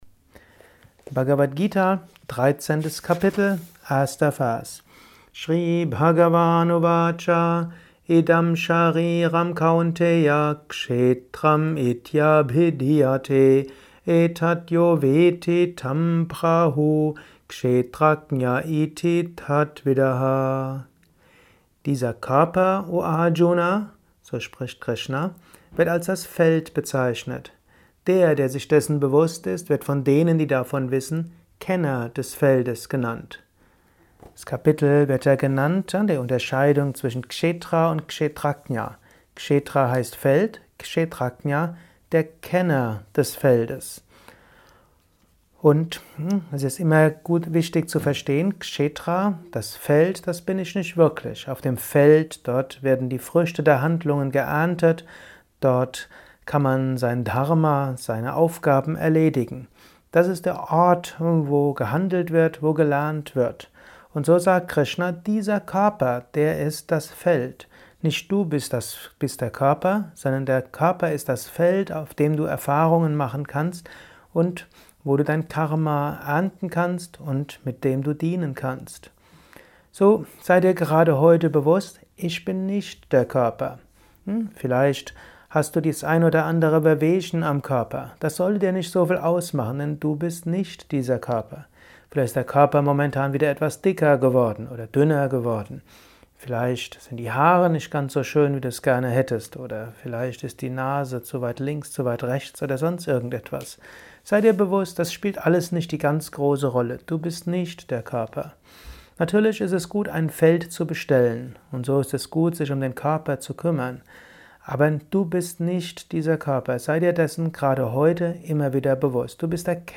Dies ist ein kurzer Kommentar als Inspiration für den